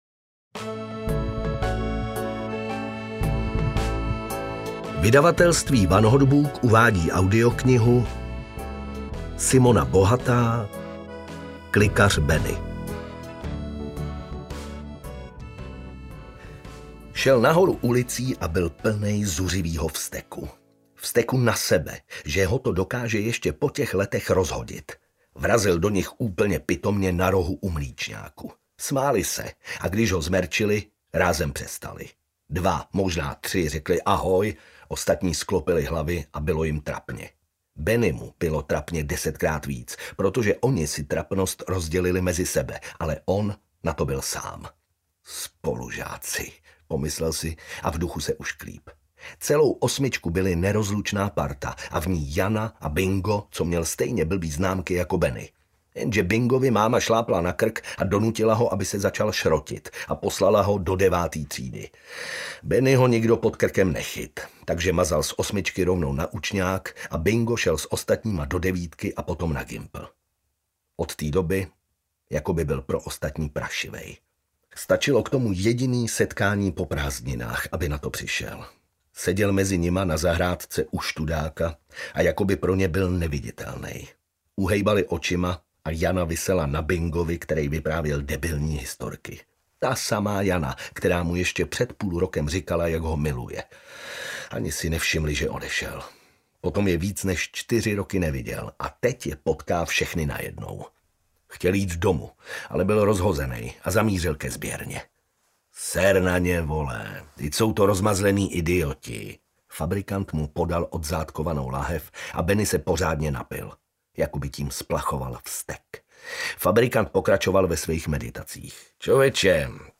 Klikař Beny audiokniha
Ukázka z knihy